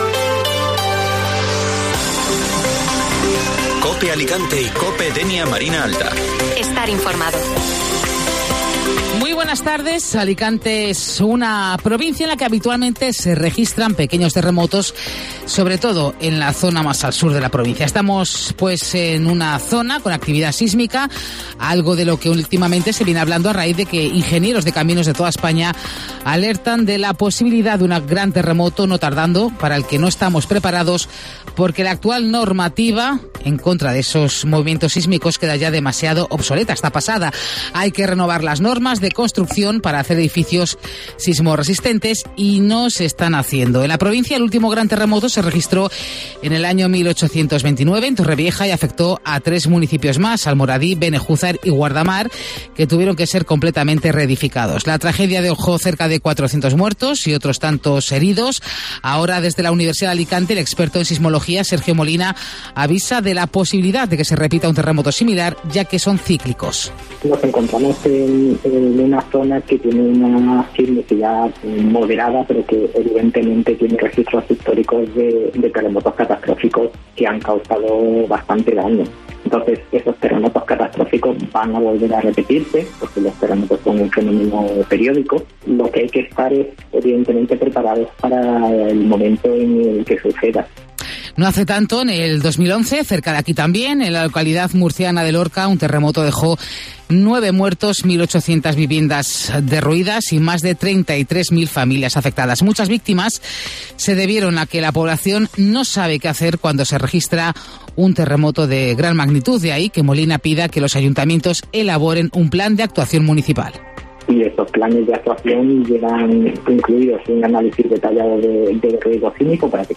Informativo Mediodía Cope Alicante (Viernes 7 de julio)